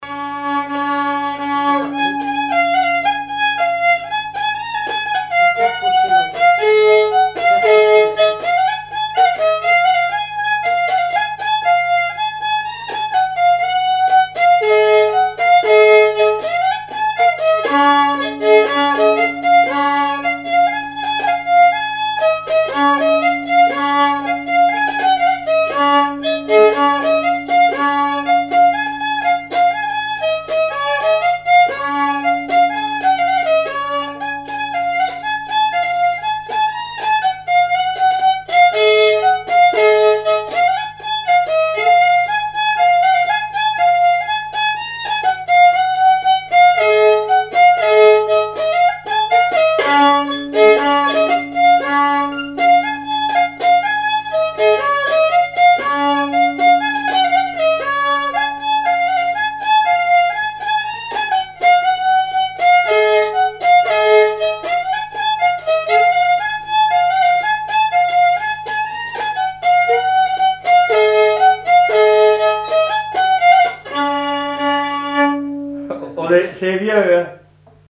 danse : polka
répertoire d'air pour la danse au violon et à l'accordéon
Pièce musicale inédite